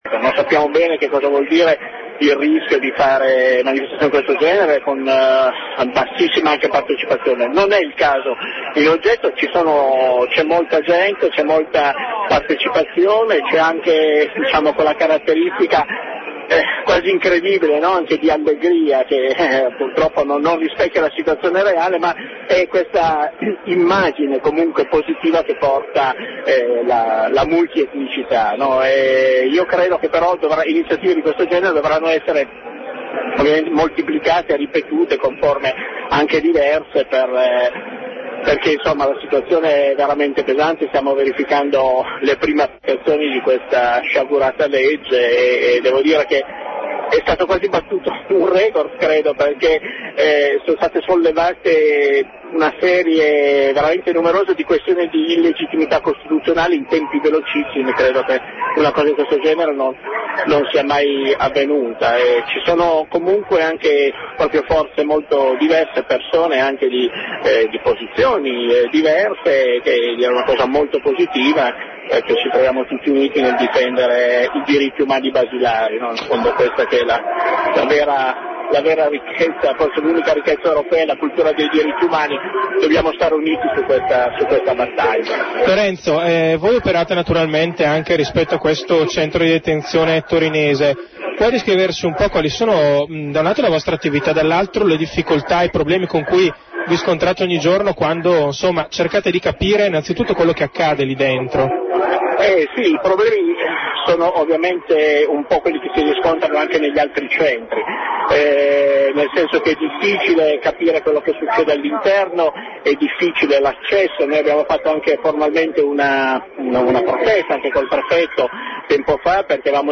Dalla manifestazione di Torino, le corrispondenze audio raccolte da Radio gap